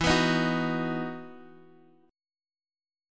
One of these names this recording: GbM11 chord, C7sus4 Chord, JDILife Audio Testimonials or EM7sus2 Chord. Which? EM7sus2 Chord